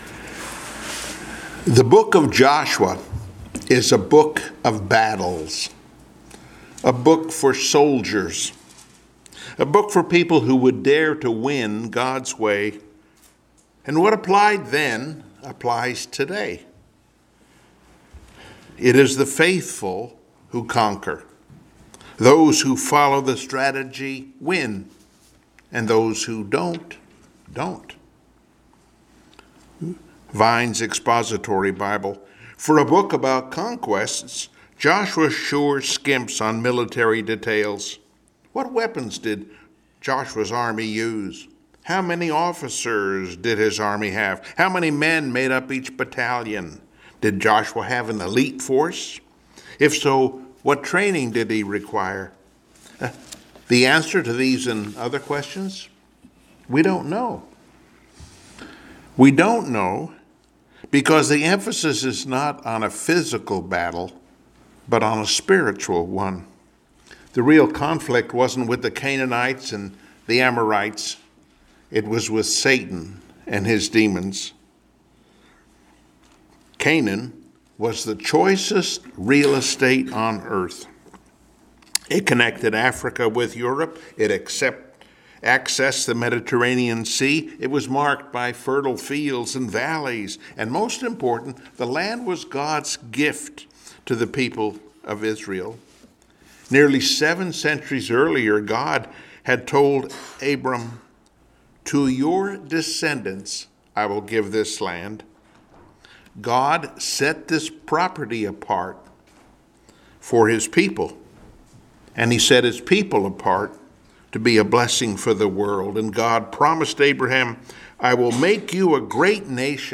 Passage: Joshua 4:1-5:12 Service Type: Sunday Morning Worship